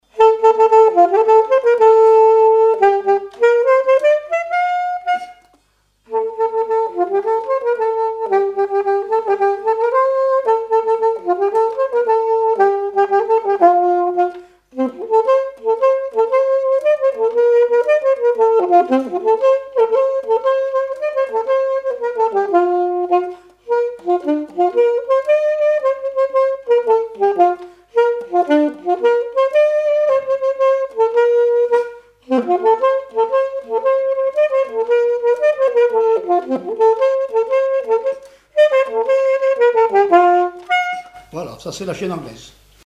danse : quadrille : chaîne anglaise
témoignages et instrumentaux
Pièce musicale inédite